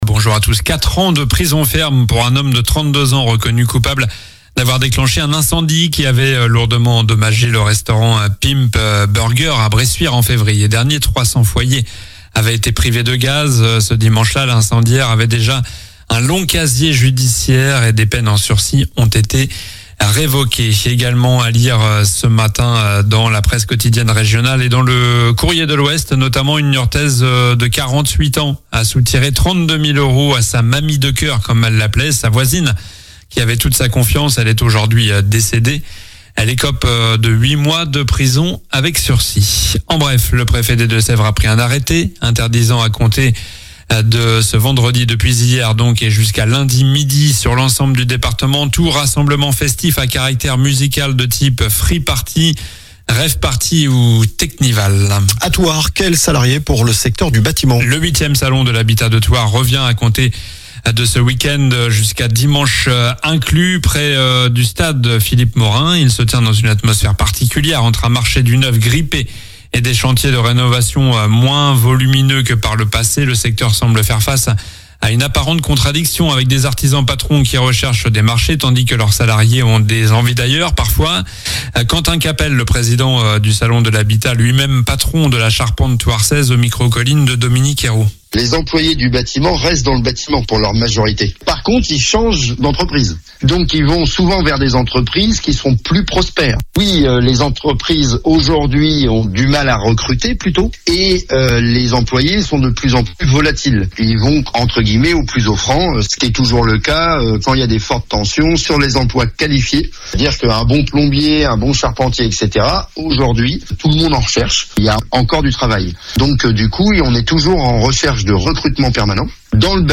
COLLINES LA RADIO : Réécoutez les flash infos et les différentes chroniques de votre radio⬦
Journal du samedi 17 mai (matin)